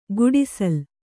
♪ guḍisal